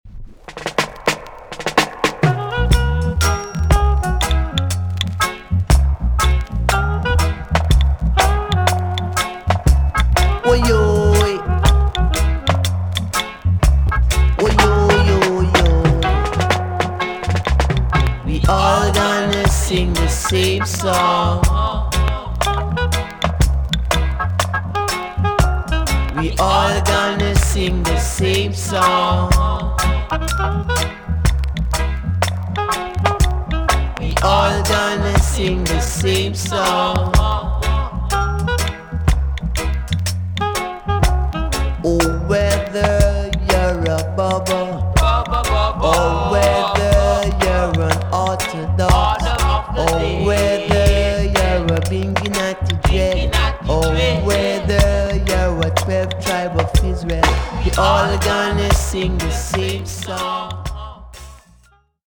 TOP >REGGAE & ROOTS
EX- 音はキレイです。
1978 , WICKED ROOTS FOUNDATION TUNE!!